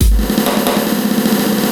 E Kit Roll.wav